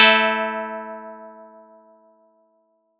Audacity_pluck_8_14.wav